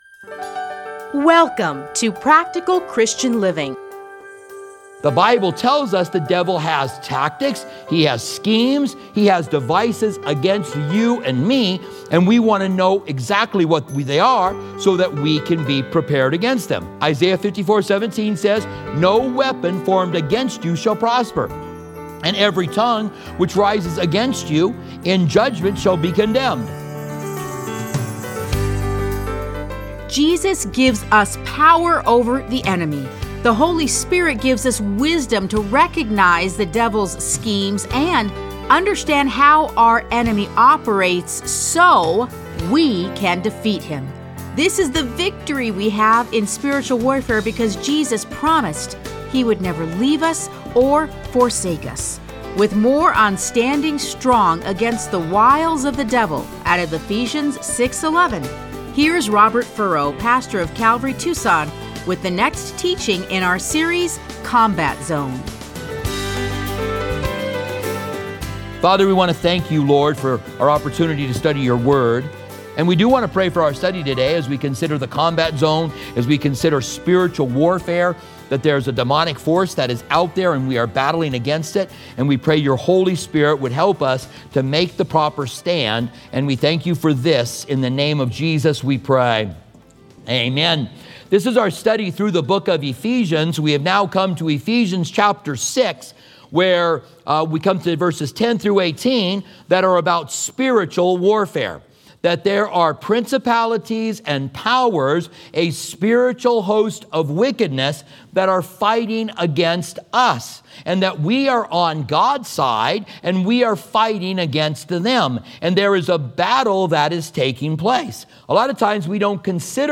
Listen to a teaching from Ephesians 6:11.